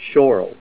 Help on Name Pronunciation: Name Pronunciation: Schorl
Say SCHORL Help on Synonym: Synonym: ICSD 74180   PDF 43-1464   Tourmaline